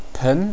pén
pen2.wav